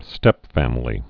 (stĕpfămə-lē, -fămlē)